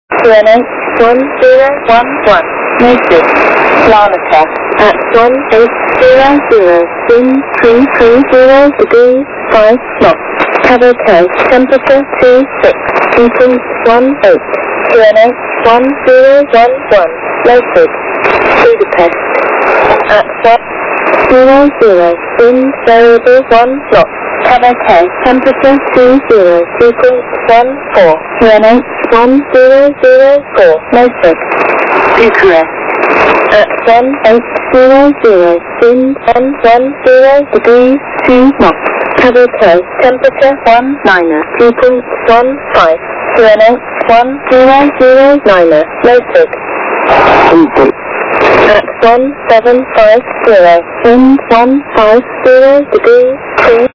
АТИС аэропорта
atisaeroporta_5450..wav